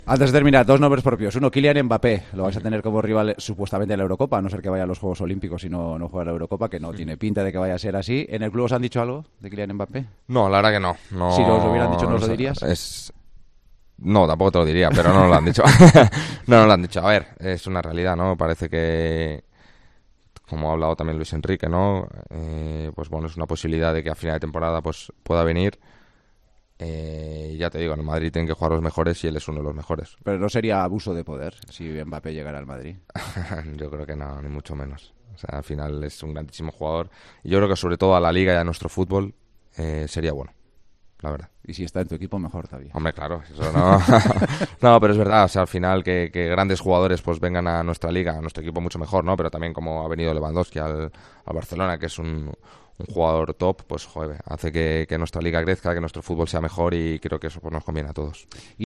El lateral del Real Madrid y de la selección española estuvo esta noche en El Partidazo de COPE desde la concentración con España, donde se prepara de cara a los encuentros amistosos ante Colombia y Brasil.
Dani Carvajal, en El Partidazo de COPE